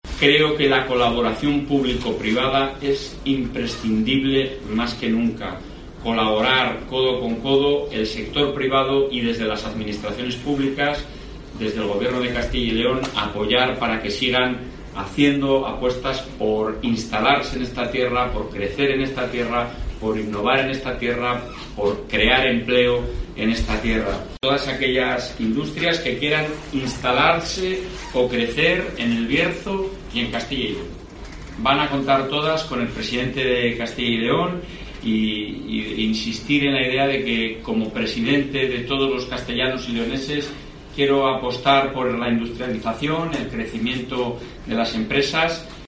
AUDIO: Escucha aquí las palabras del presidente de la Junta